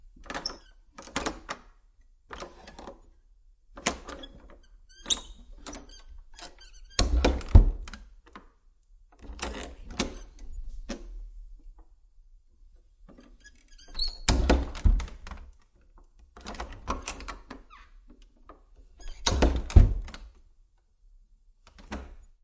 Door open inside01
描述：Opening a wooden door with a squeaky metal handle. Natural indoors reverberation.
标签： squeaky door wood inside open
声道立体声